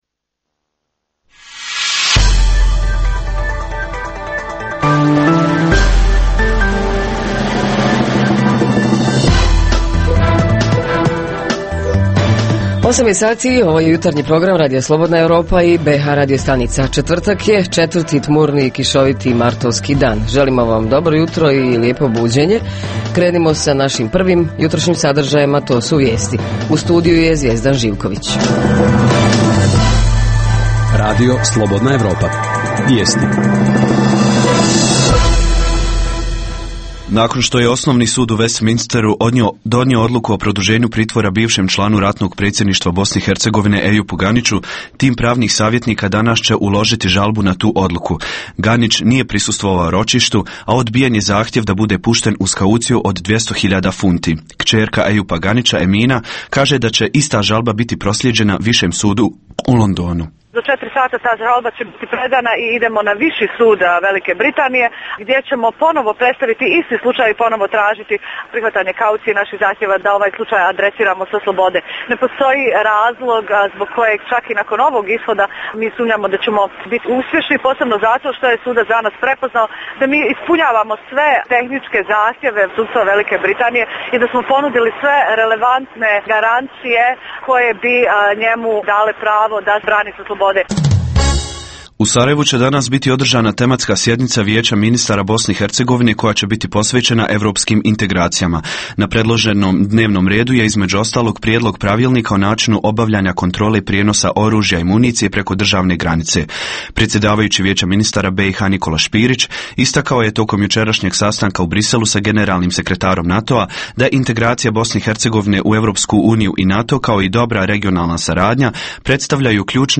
Prava socijalno ugroženih i da li se ostvaraju u praksi? Reporteri iz cijele BiH javljaju o najaktuelnijim događajima u njihovim sredinama.
Redovni sadržaji jutarnjeg programa za BiH su i vijesti i muzika.